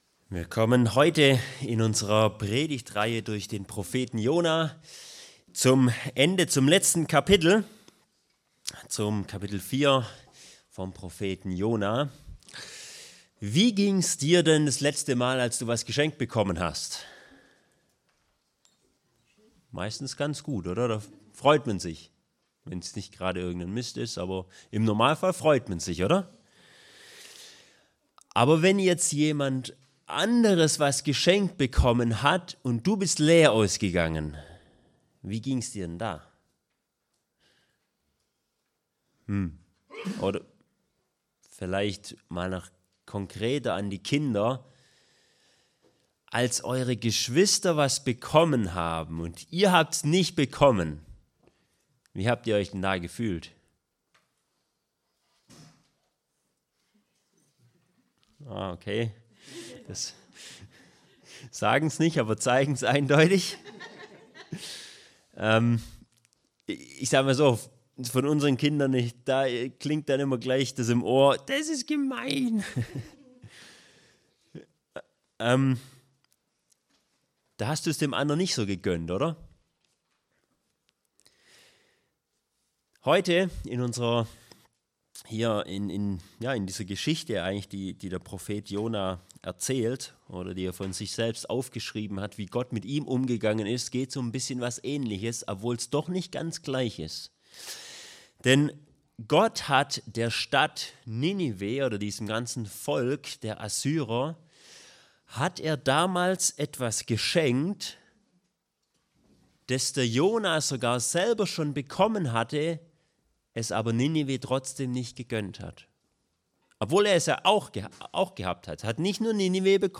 Predigtreihe: Jona Auslegungsreihe